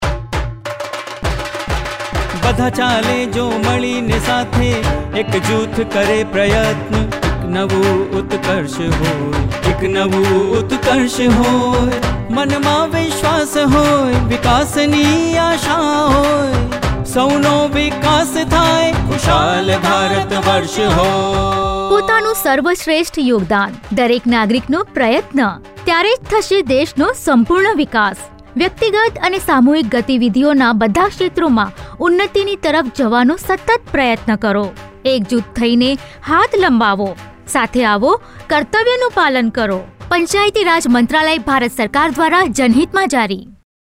67 Fundamental Duty 10th Fundamental Duty Strive for excellence Radio Jingle Gujrati